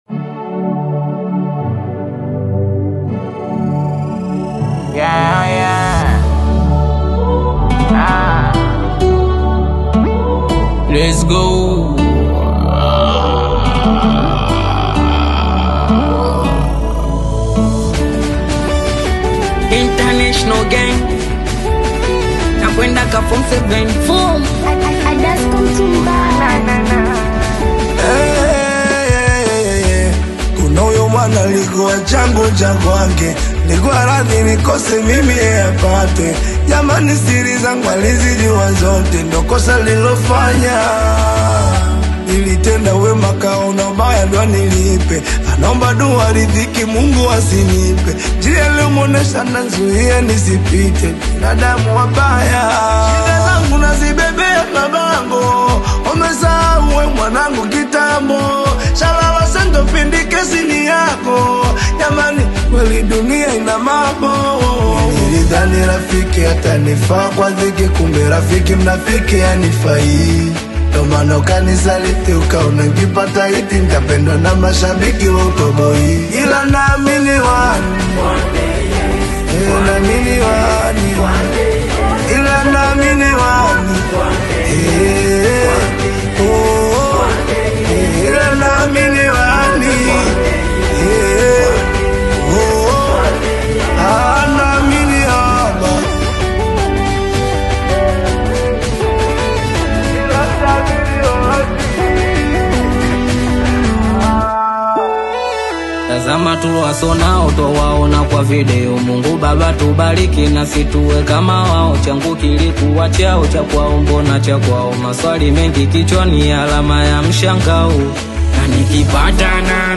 melodic Bongo Flava
high-tempo street energy